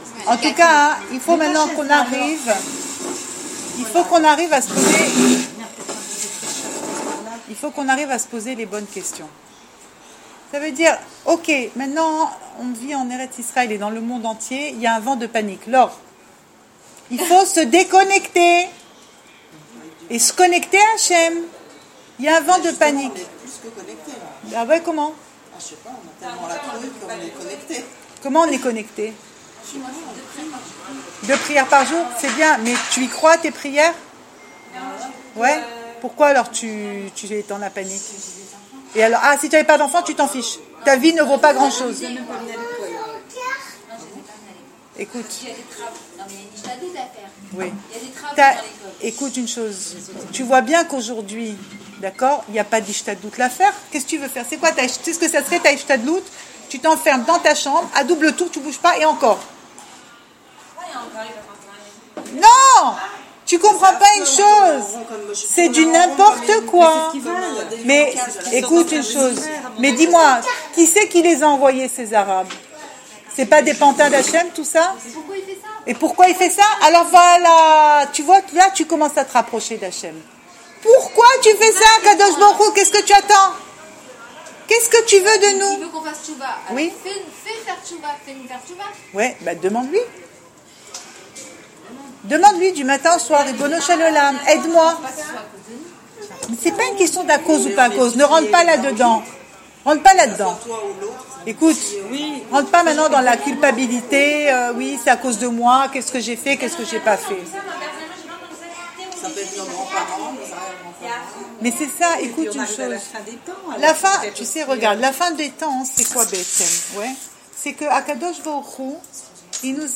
Cours audio
Enregistré à Raanana